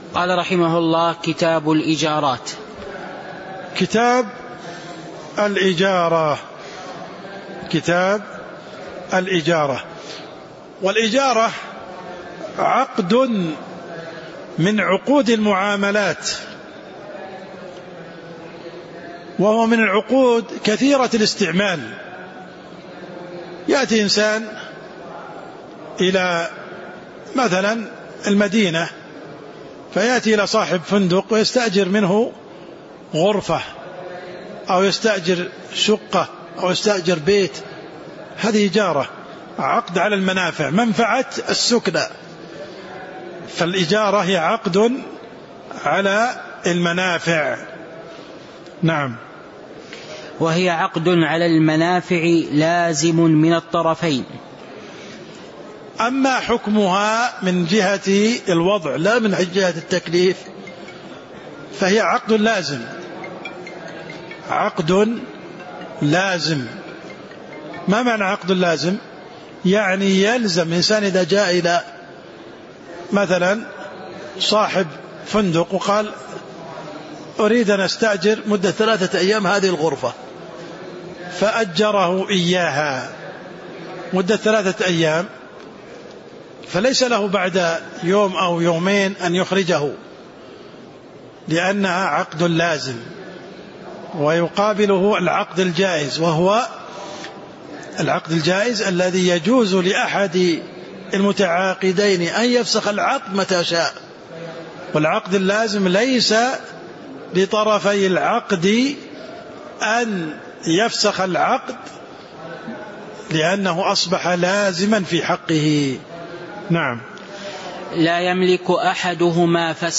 تاريخ النشر ٨ جمادى الآخرة ١٤٤٣ هـ المكان: المسجد النبوي الشيخ: عبدالرحمن السند عبدالرحمن السند قوله: وهي عقد المنافع لازم من الطرفين (01)